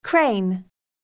반복듣기 [krein] 듣기